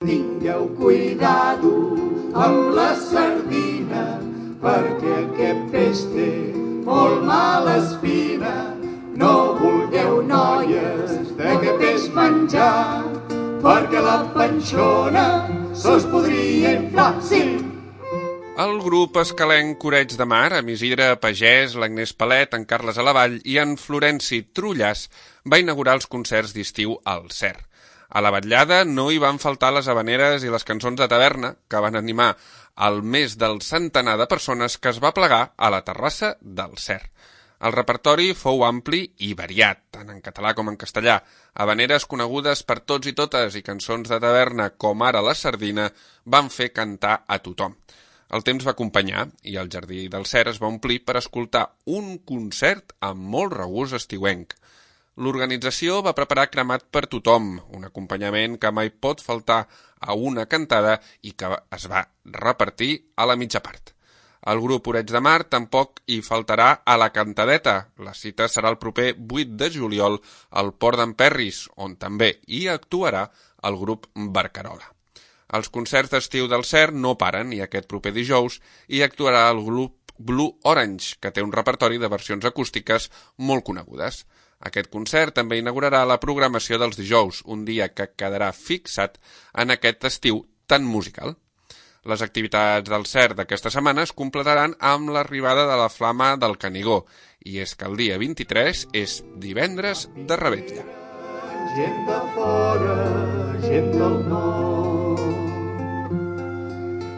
L'ambient d'estiu ha acompanyat el concert, on s'hi han atansat més d'un centenar de persones.
El repertori fou ampli i variat, tant en català com en castellà, havaneres conegudes per tots i totes i cançons de taverna com ara la sardina, van fer cantar a tothom.